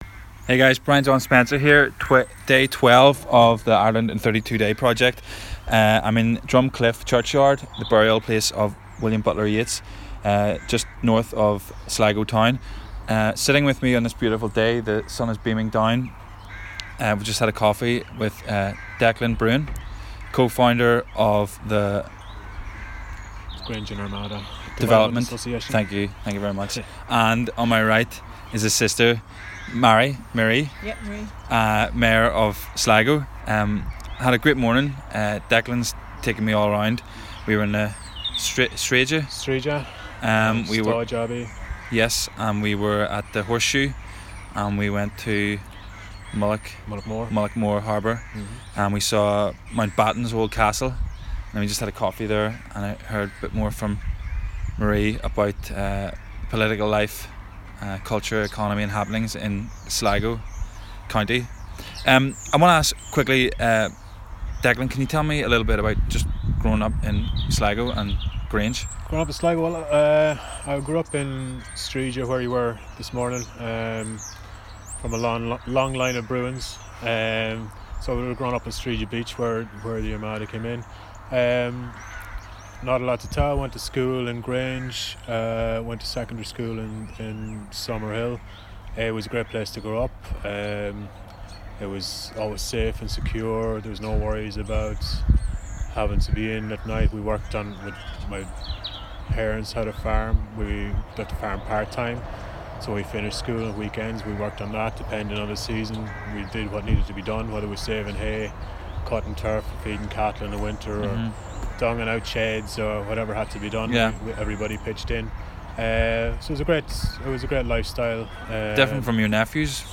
in Drumcliff church yard, Co. Sligo.